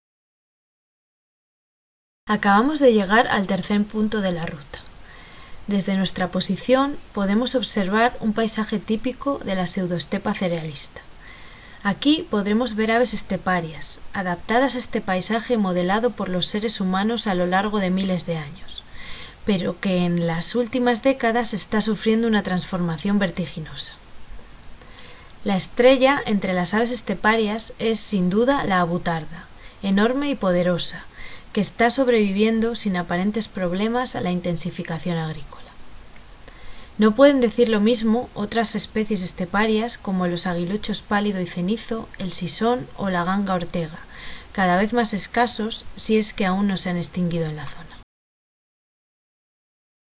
Audio descripción: